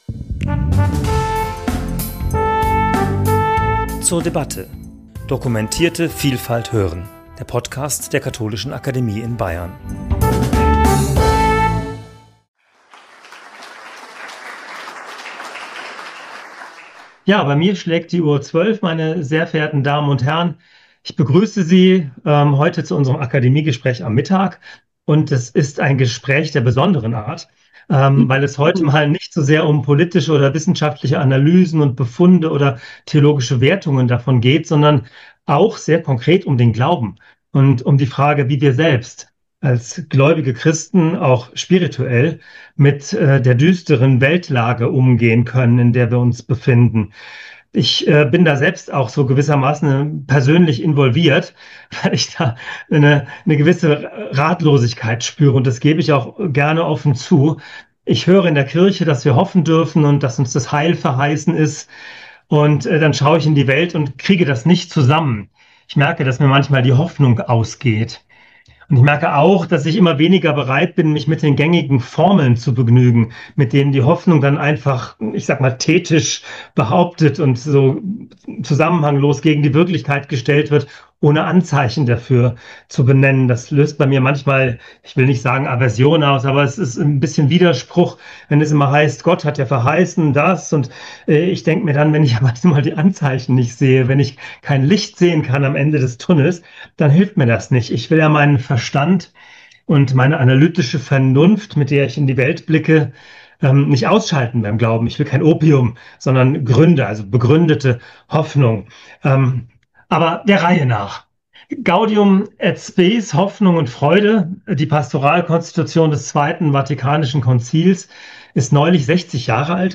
Gespräch zum Thema 'Luctus et angor (Trauer & Angst)“: die Minus-Zeichen der Zeit deuten!'